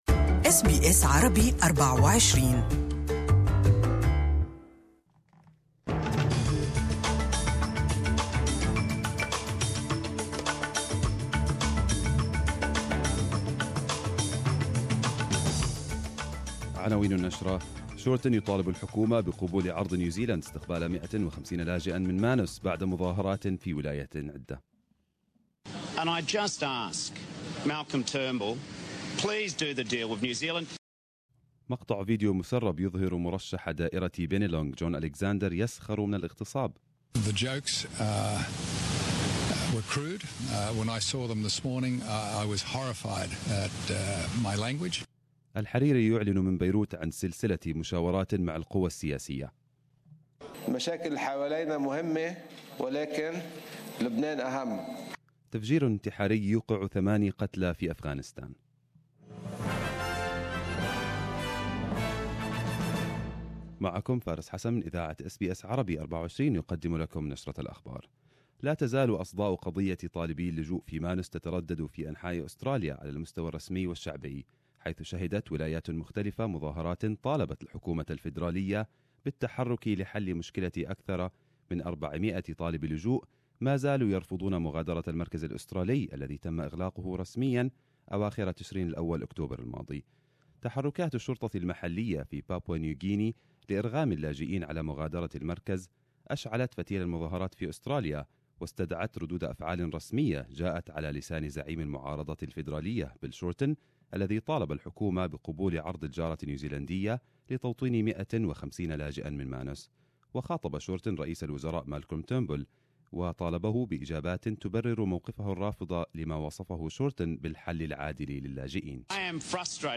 نشرة مفصّلة للأنباء من Good Morning Australia